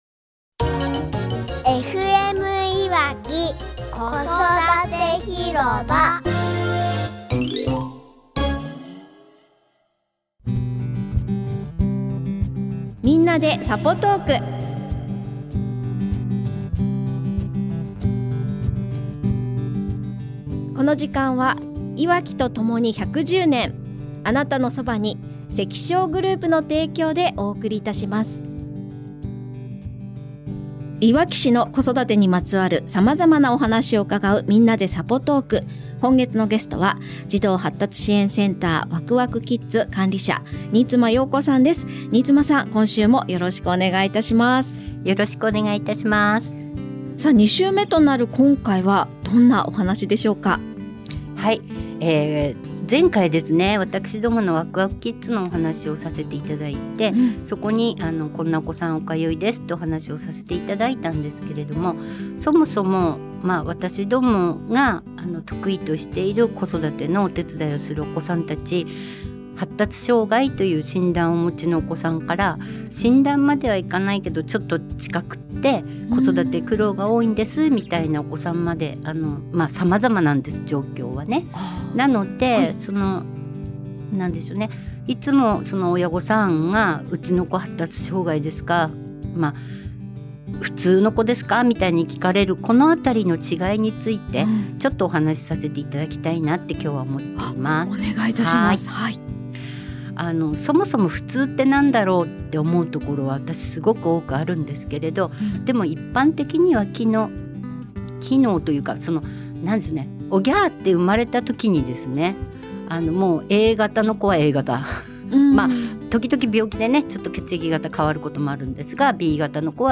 【5月のゲスト】